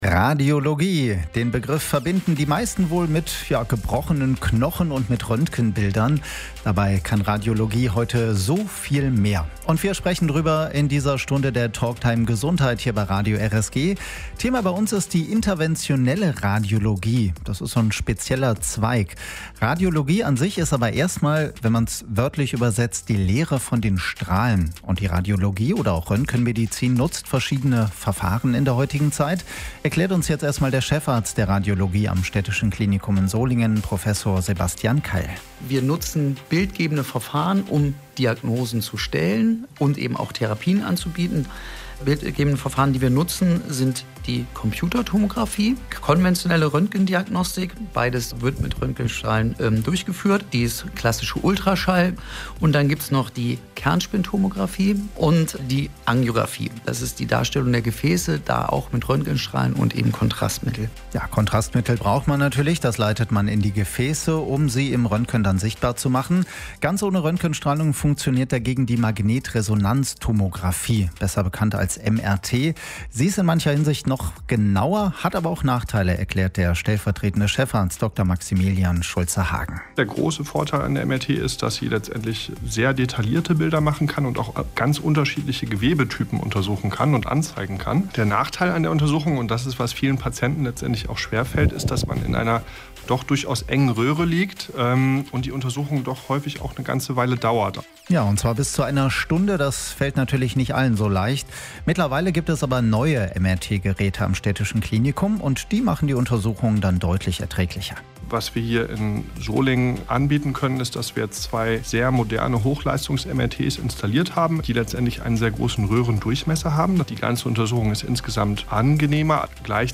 Die Radiosprechstunde lief am Samstag, 25. Juli 2025, von 12 bis 13 Uhr bei Radio RSG und kann hier nachgehört werden.